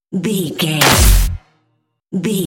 Dramatic hit hiss electricity debris
Sound Effects
Atonal
heavy
intense
dark
aggressive
hits